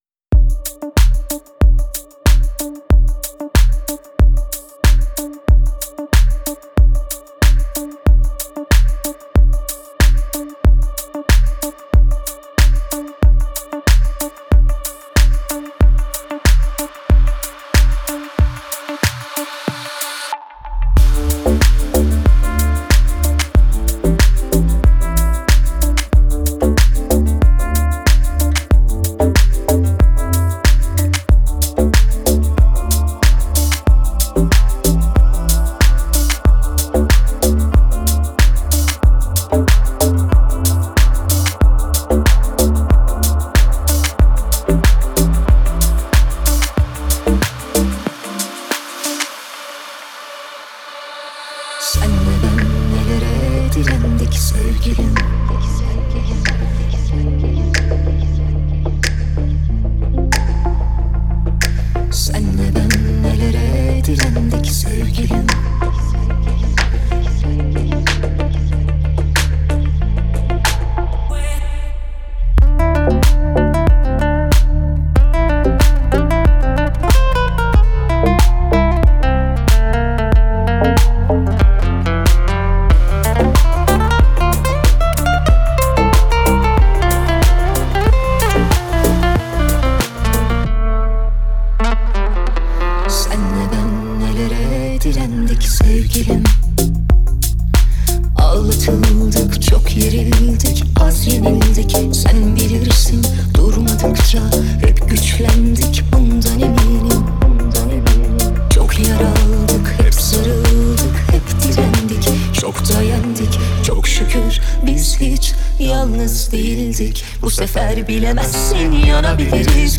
это яркая и зажигательная композиция в жанре поп